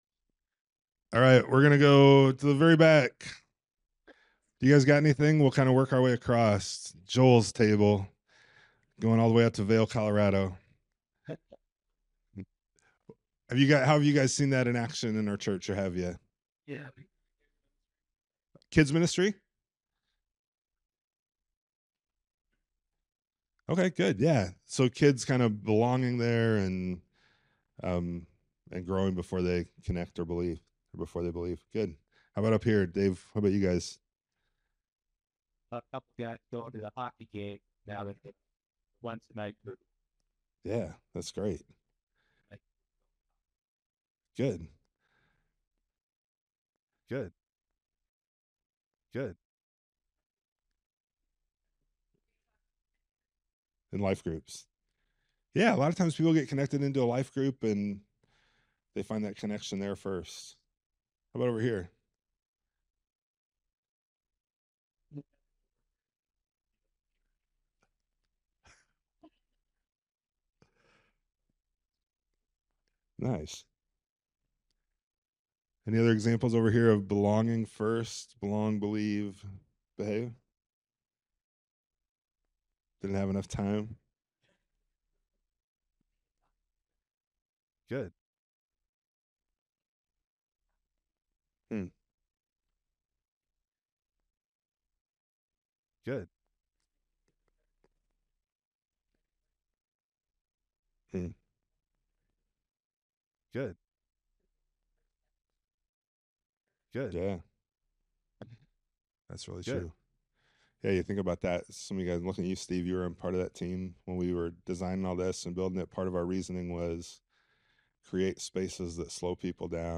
Seminar recordings from Evangelism Shift.